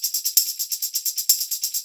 130 TAMB2.wav